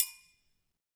Triangle6-HitFM_v2_rr2_Sum.wav